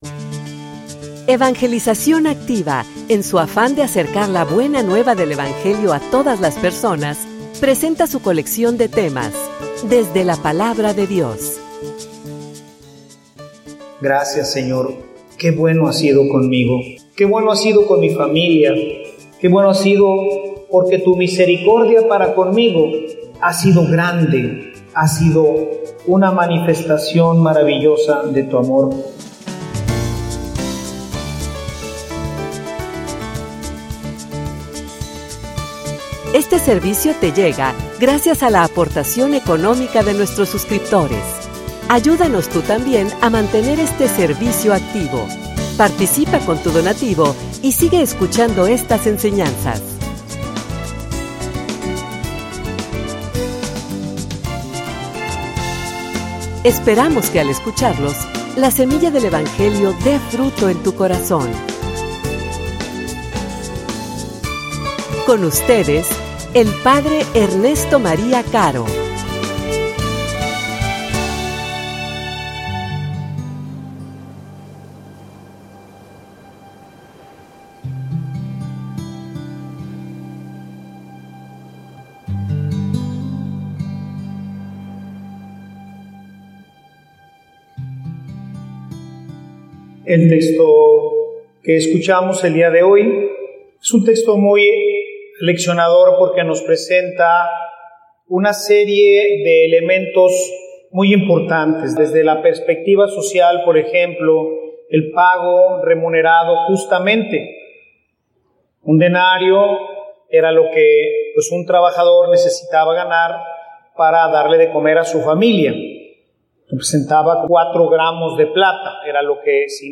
homilia_Agradecer.mp3